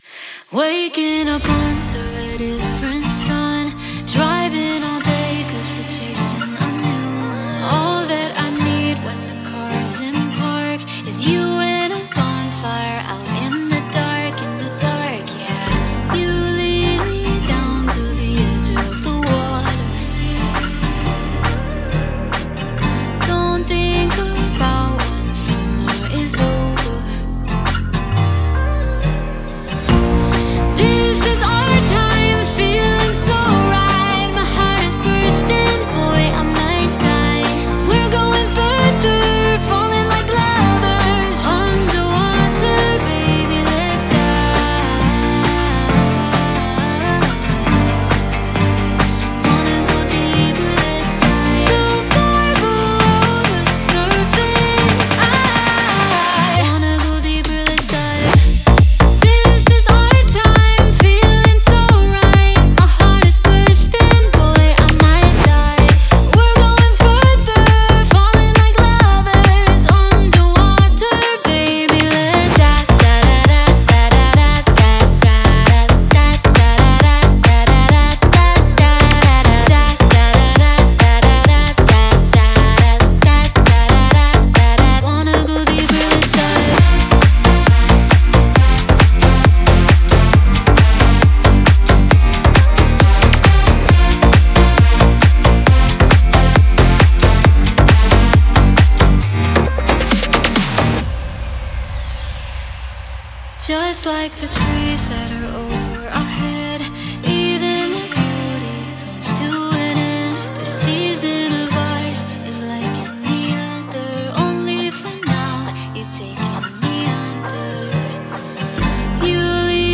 это вдохновляющая поп-песня